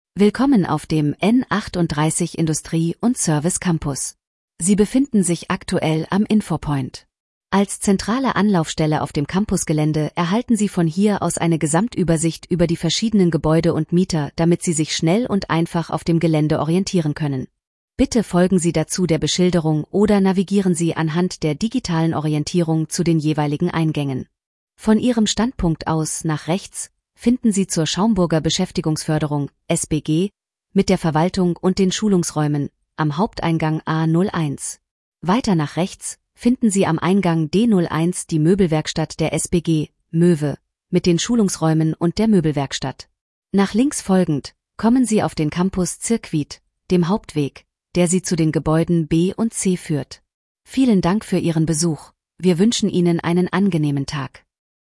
n38_wayfinding_audioansage_01_lang_INFOPOINT.mp3